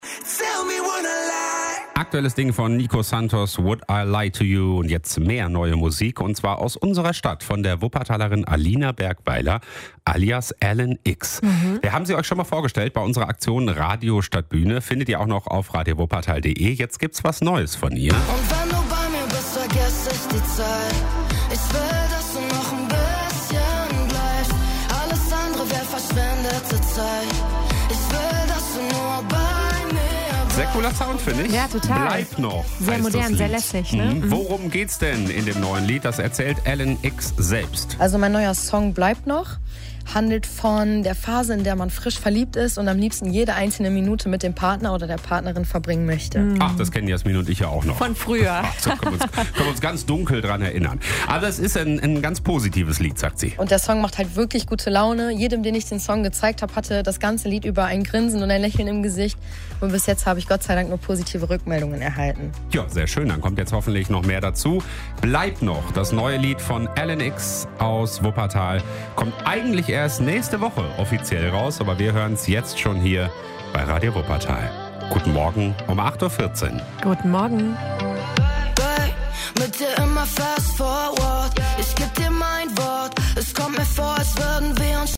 Deutsch-Pop.